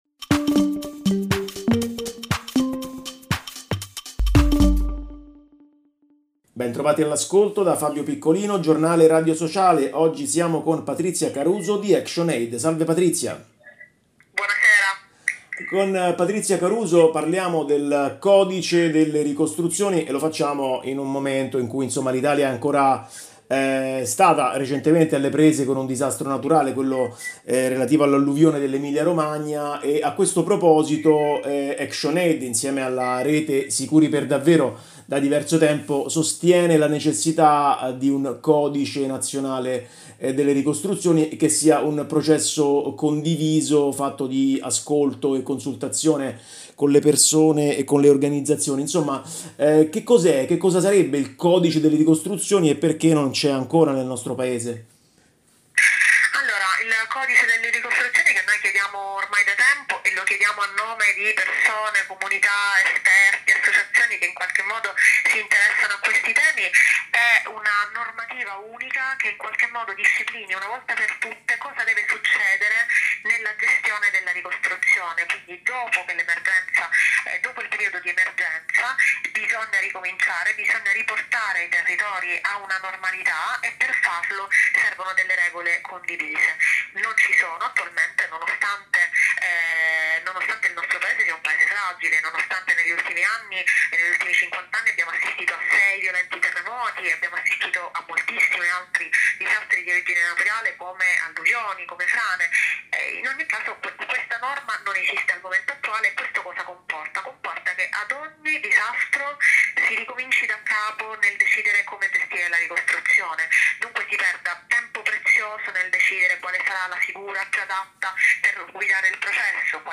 Archivi categoria: Intervista